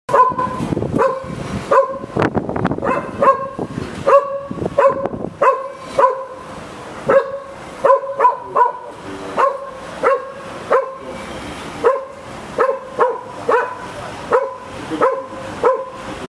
Dog Barks At A Cat Sound Effect Download: Instant Soundboard Button